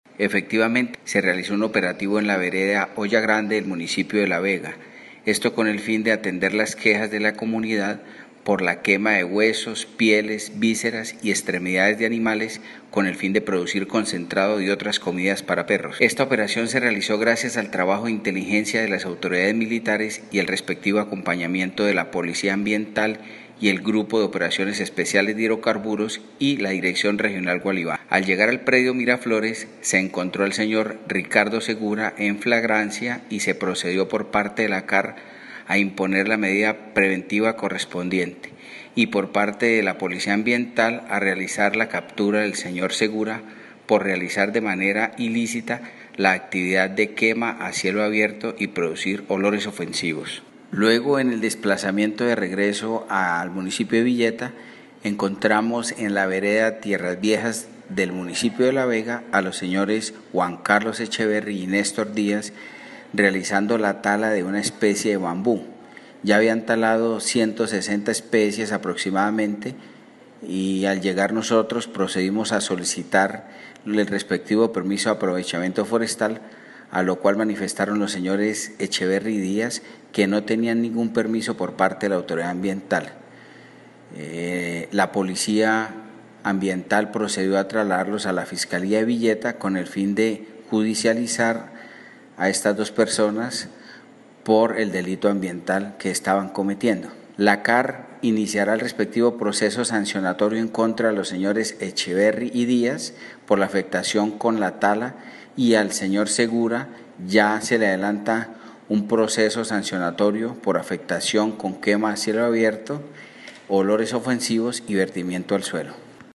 Voz ingeniero Yimmy Hernández Ruiz Director Regional Gualiva CAR